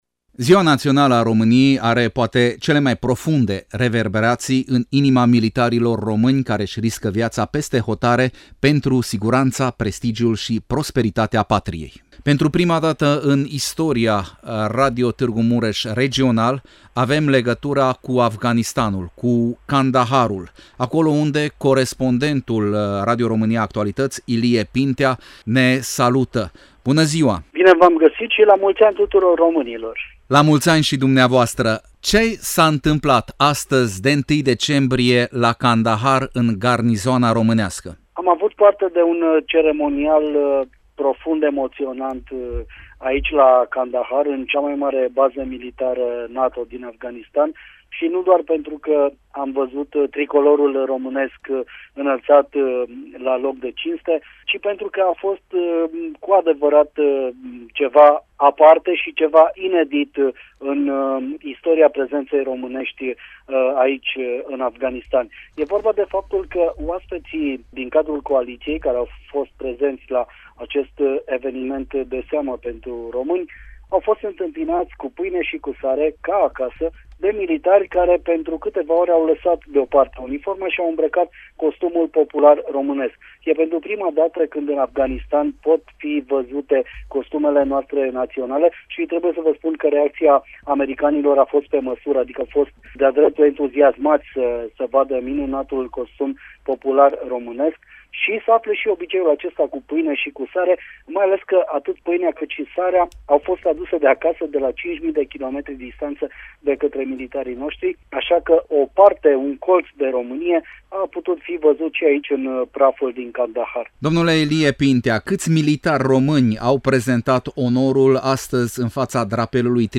La Kandahar, în cea mai mare bază militară aliată din Afganistan, festivităţile dedicate Zilei Naţionale a României au cuprins un ceremonial militar şi religios.